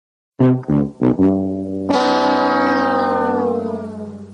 Boo Sound Effect Download: Instant Soundboard Button
Boo Sound Button - Free Download & Play